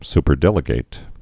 (spər-dĕlĭ-gāt, -gĭt)